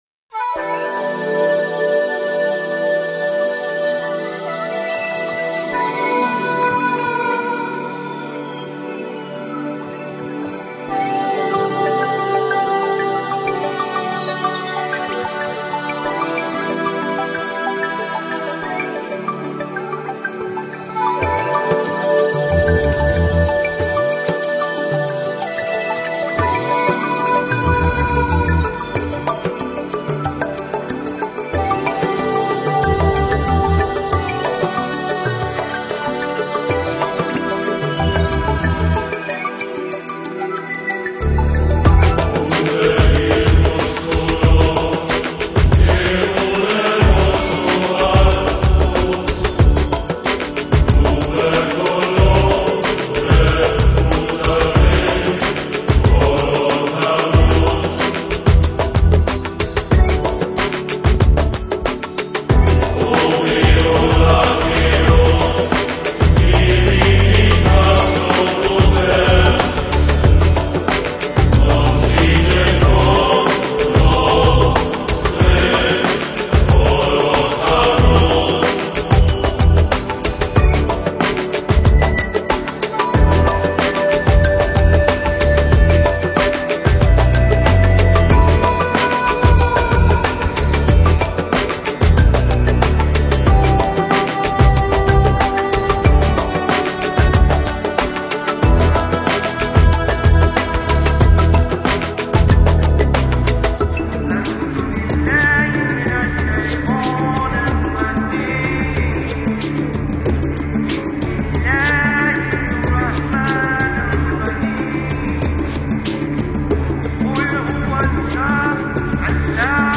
Мистическая музыка